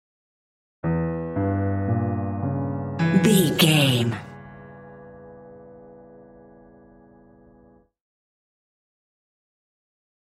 Diminished
scary
tension
ominous
dark
haunting
eerie
stinger
short music instrumental
horror scene change music